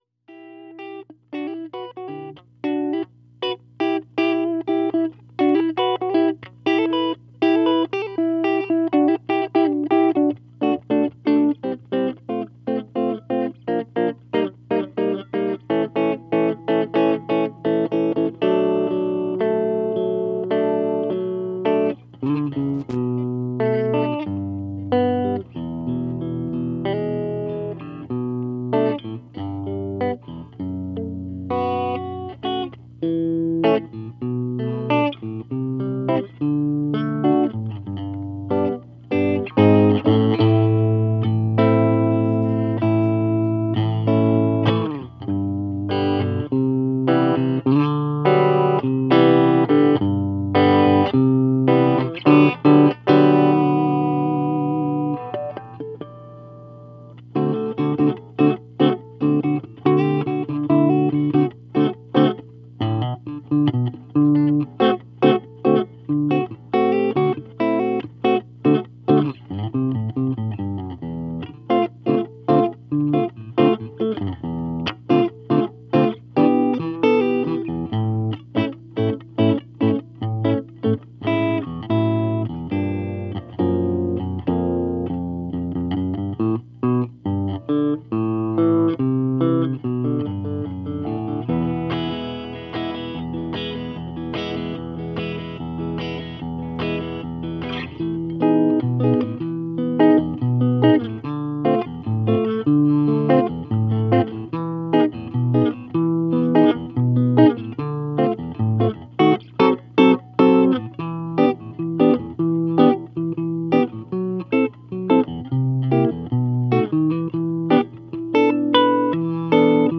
These were taken straight from mic-to-recording, with no processing.
The Single Coil Sound
Single Coil Finger Picked
With the Mr. V Arena Verb, and a BBE AM64 Drive
Crunchy Overdriven Single Coil